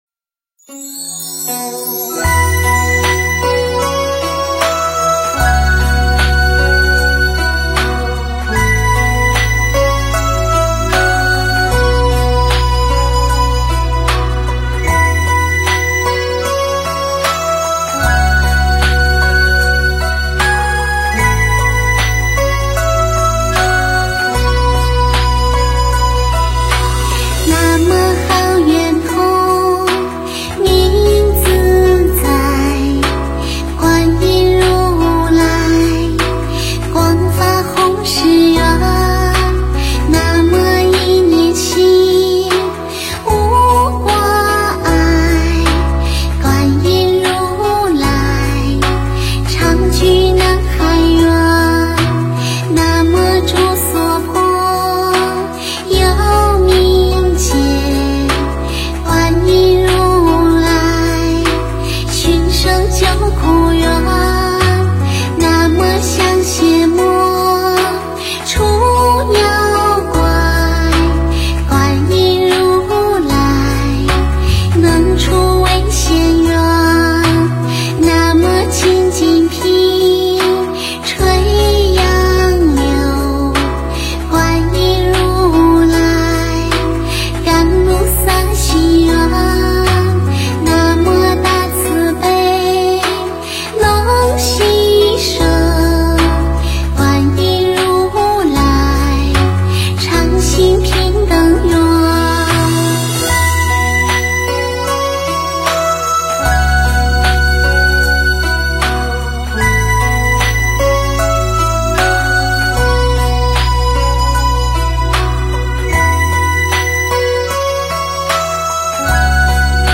佛音 诵经 佛教音乐 返回列表 上一篇： 忏悔文 下一篇： 思源 相关文章 佛歌--风雪禅音 佛歌--风雪禅音...